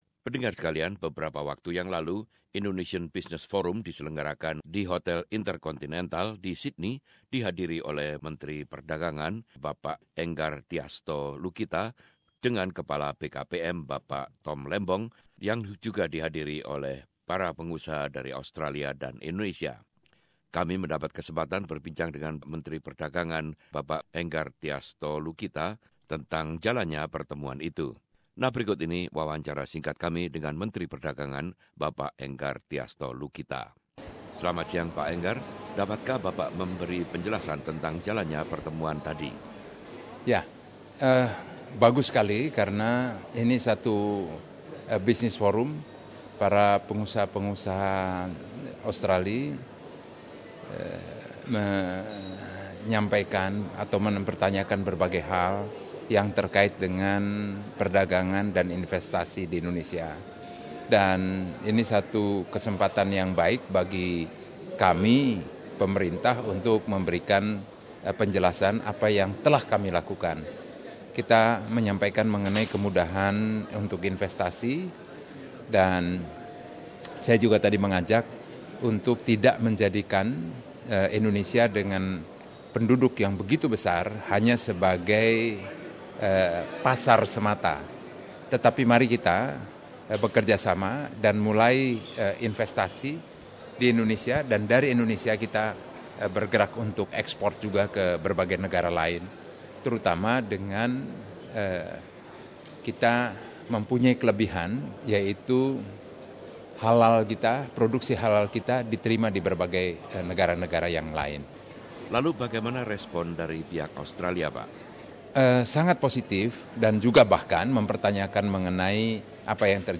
Wawancara dengan Menteri Perdagangan Indonesia Enggartiasto Lukita setelah pertemuan Business Forum yang diselenggarakan di Hotel Intercontinental di Sydney.